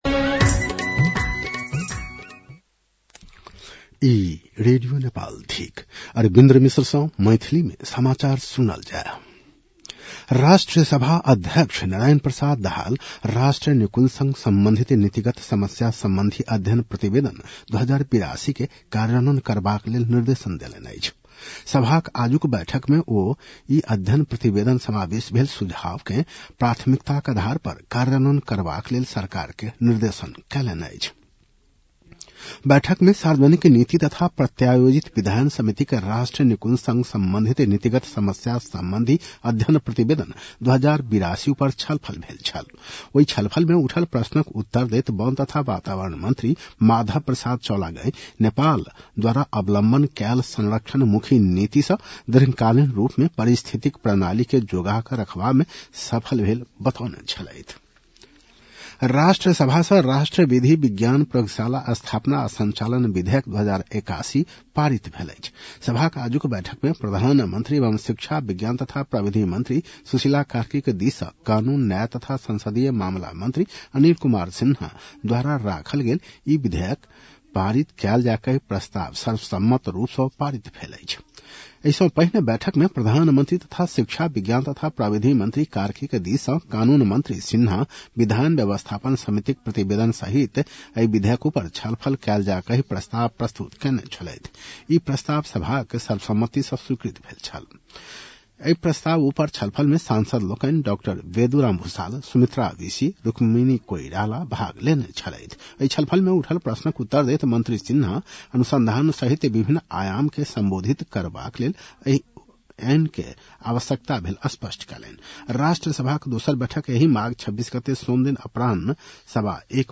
मैथिली भाषामा समाचार : २३ माघ , २०८२
Maithali-NEWS-10-23.mp3